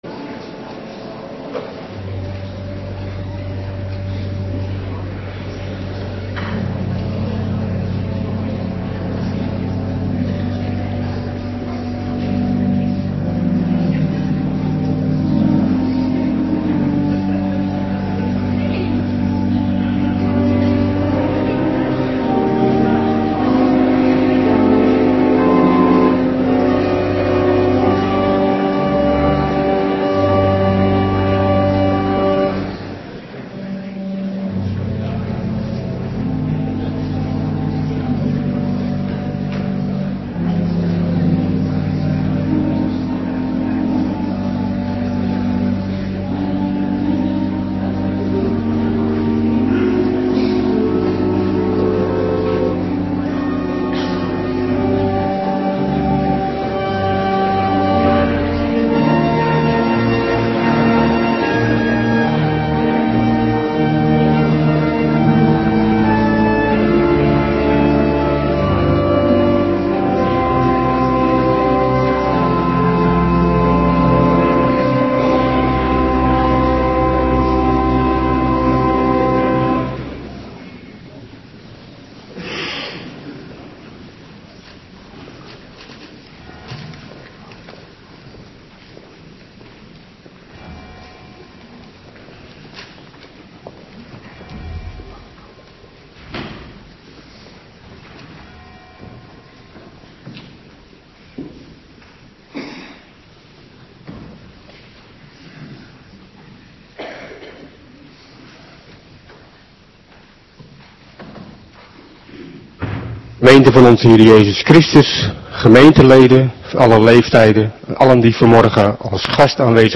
Morgendienst 15 februari 2026